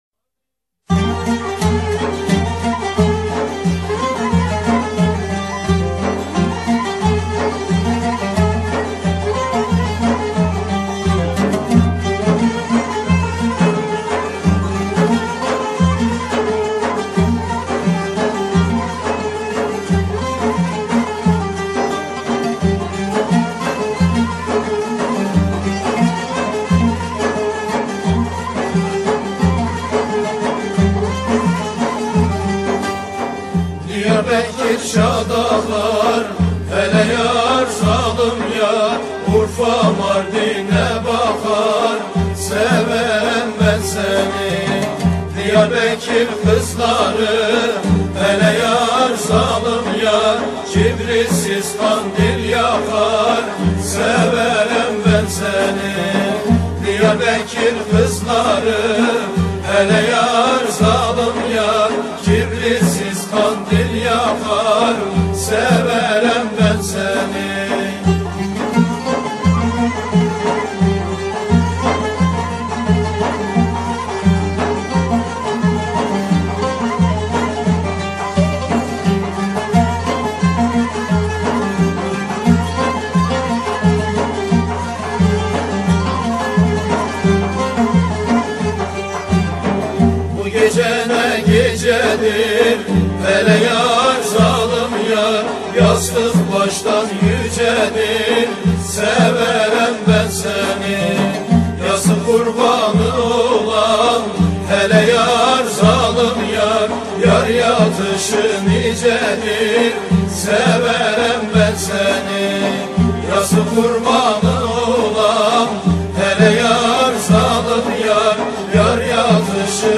Etiketler: türkiye, türkü